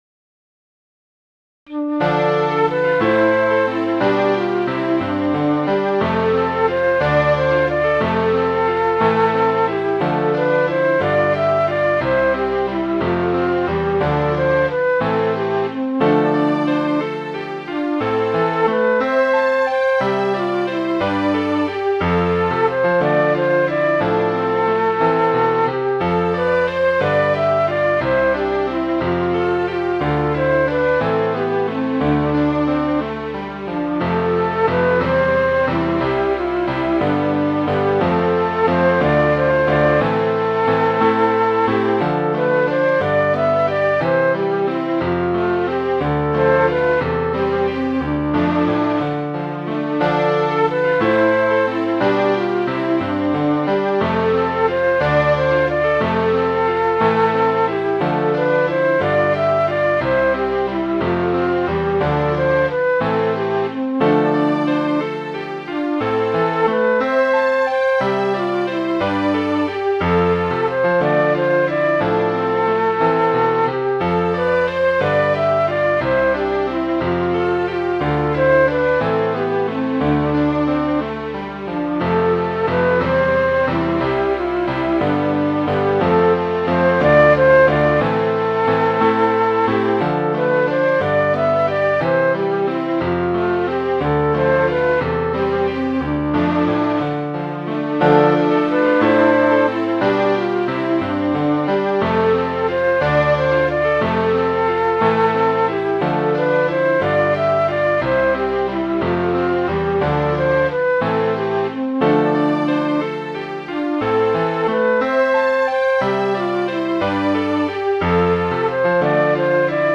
Midi File